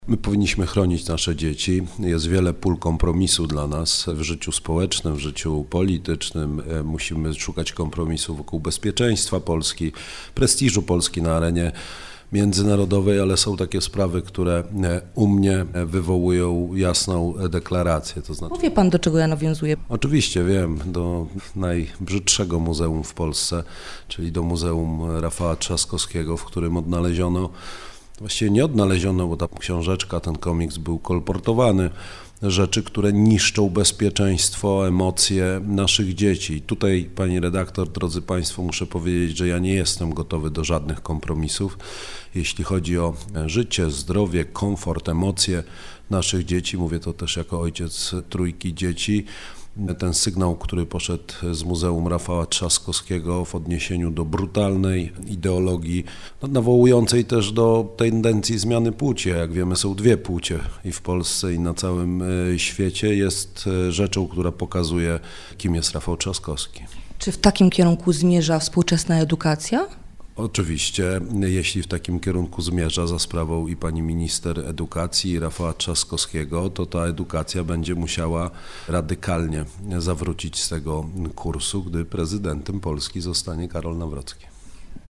Karol Nawrocki – kandydat na urząd prezydenta popierany przez PiS był gościem Radia Rodzina. Rozmawialiśmy o patriotyzmie, kierunku polskiej edukacji, redukcji liczby godzin religii, światopoglądzie.